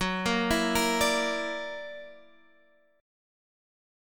Gb+7 chord